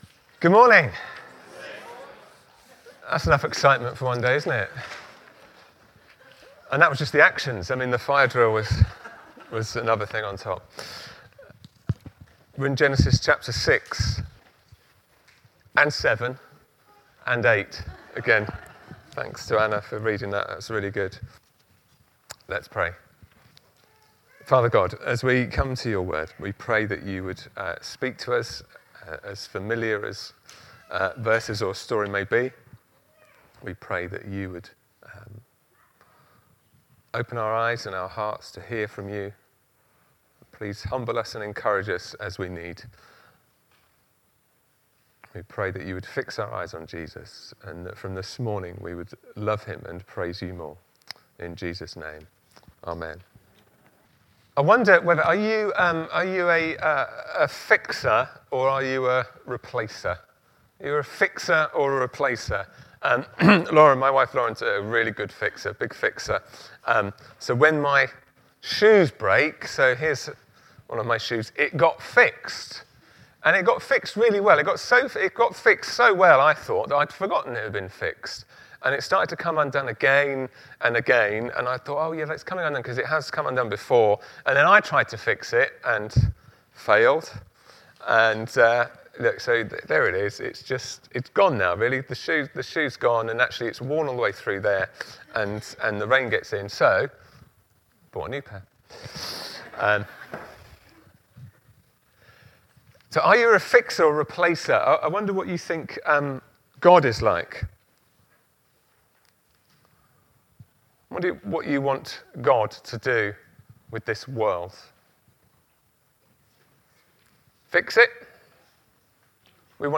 30 March 2025 Grace and Judgement Preacher